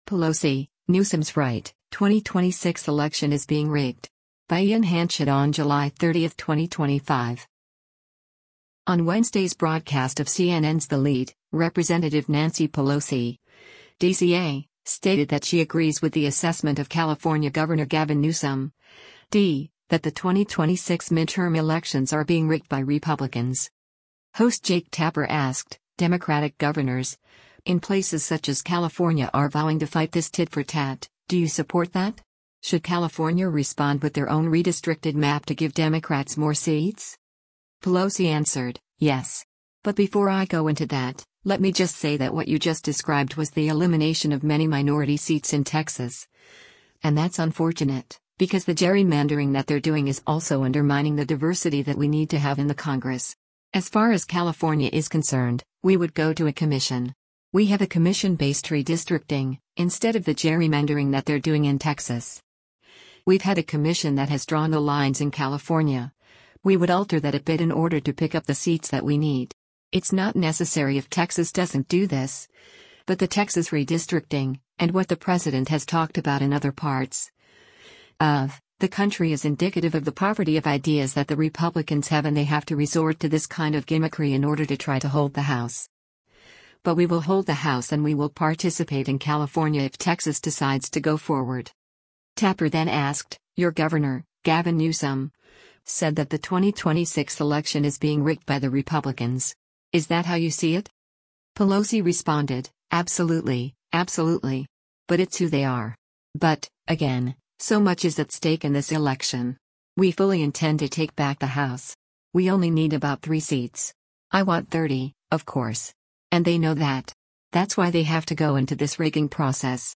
On Wednesday’s broadcast of CNN’s “The Lead,” Rep. Nancy Pelosi (D-CA) stated that she agrees with the assessment of California Gov. Gavin Newsom (D) that the 2026 midterm elections are being “rigged” by Republicans.